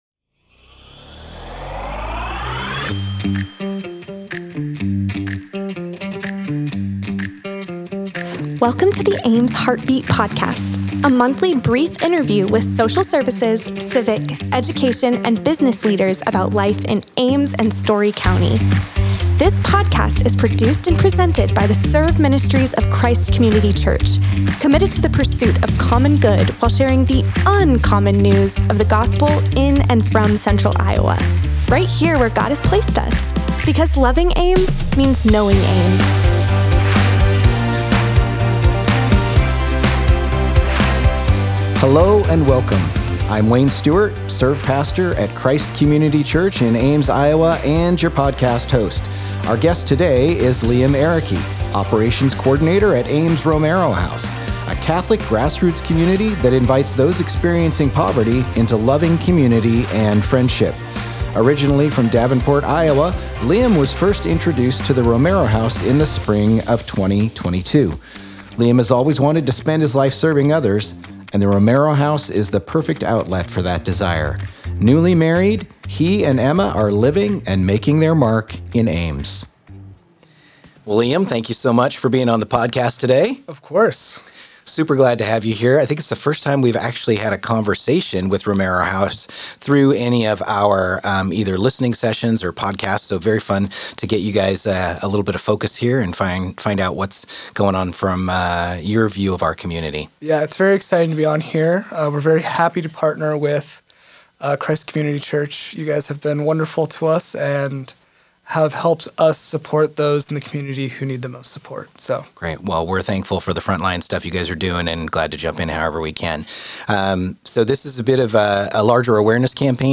The Ames Heartbeat Podcast features brief interviews with civic, education, social services, and business leaders in Ames and surrounding communities.